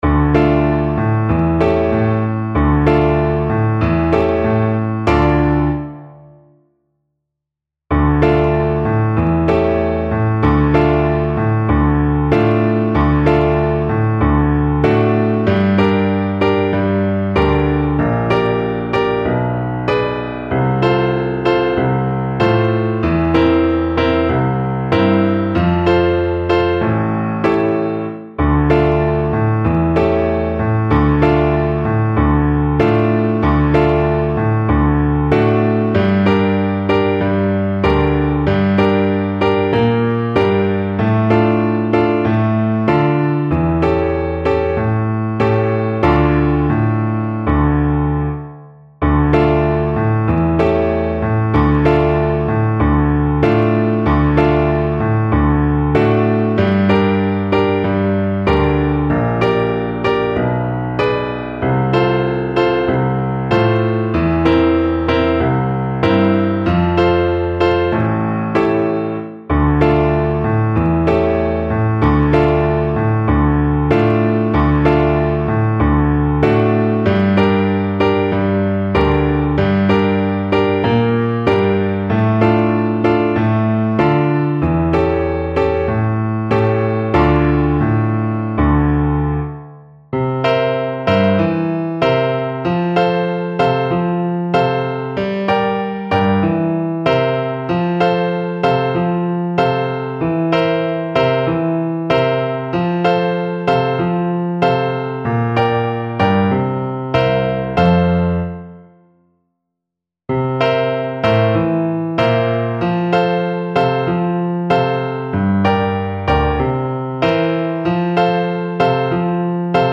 Play (or use space bar on your keyboard) Pause Music Playalong - Piano Accompaniment Playalong Band Accompaniment not yet available transpose reset tempo print settings full screen
Flute
F major (Sounding Pitch) (View more F major Music for Flute )
Steadily =c.69
2/4 (View more 2/4 Music)
Brazilian Choro for Flute
gonzaga_cubanita_FL_kar3.mp3